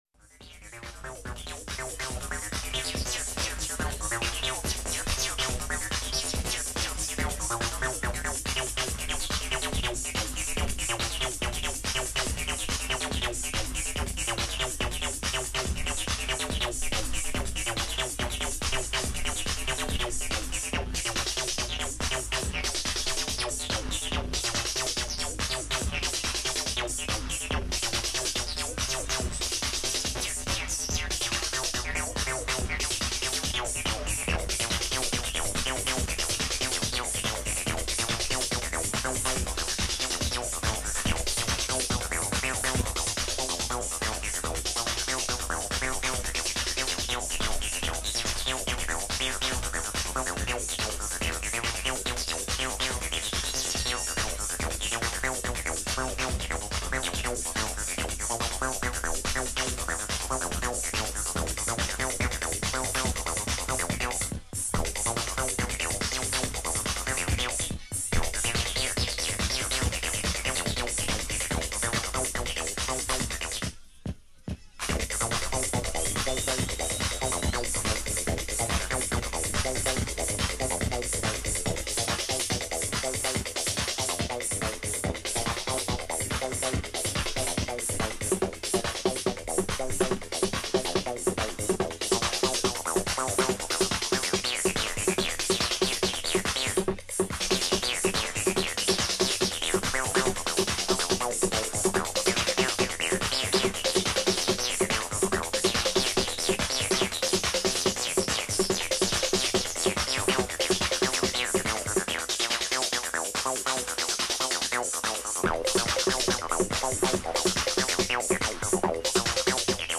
tracklist for a mix